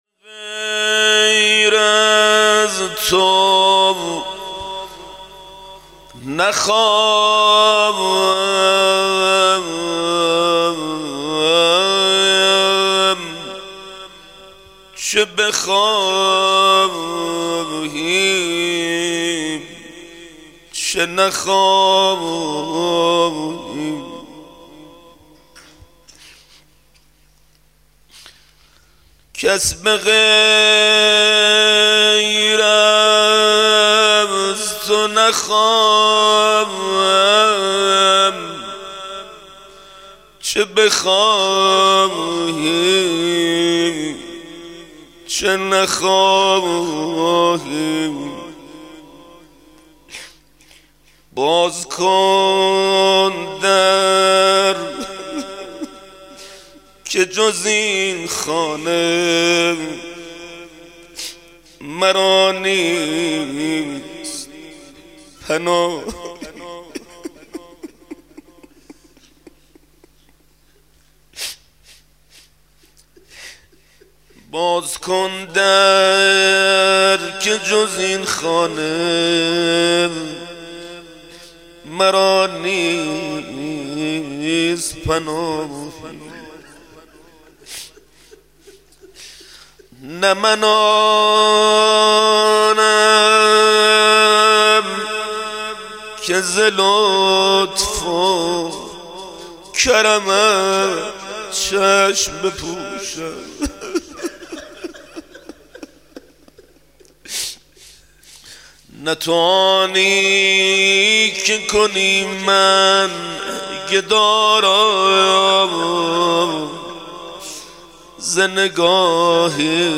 مناجات و ذکر توسل ویژۀ ایام ماه رجب ، شعبان و ماه مبارک رمضان